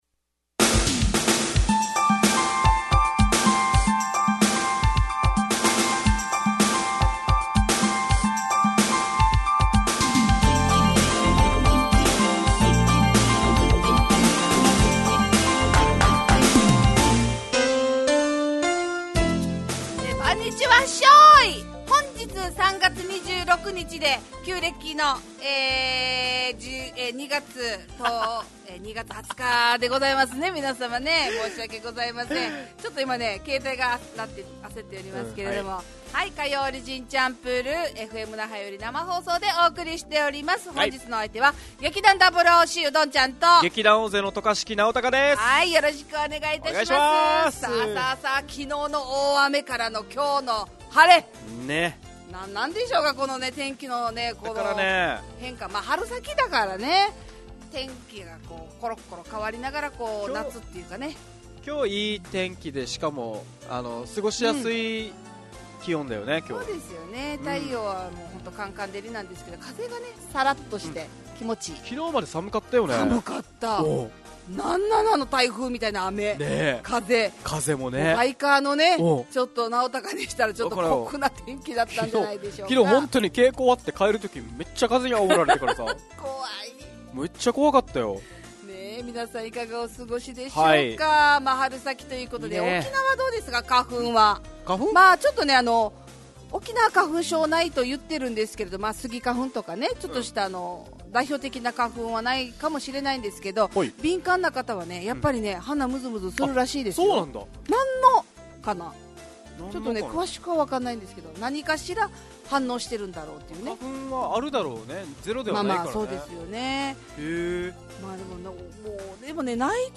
沖縄のFMラジオ局 fm那覇。